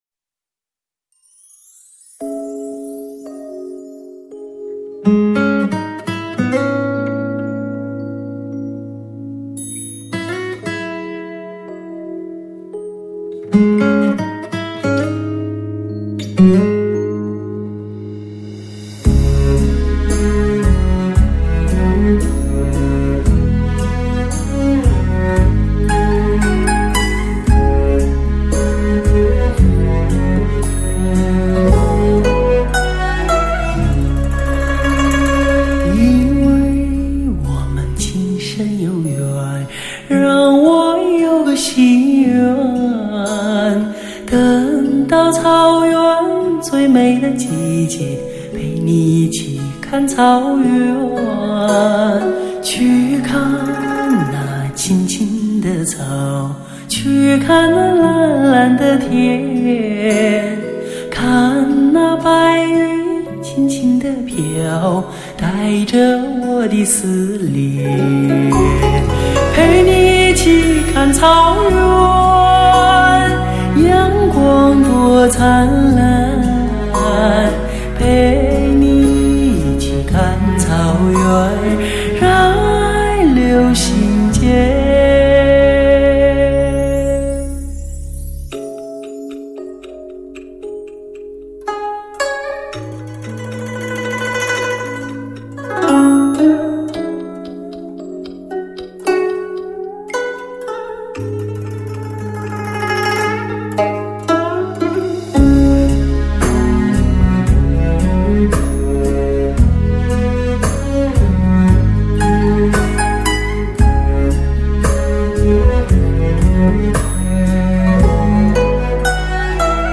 专辑格式：DTS-CD-5.1声道
圣洁的草原，绚丽的西域，宁静的草原，原生态的自然声音......
24K德国HD金碟，采用极品发烧级的方铜线材，真空麦克风荷电源处理器录制，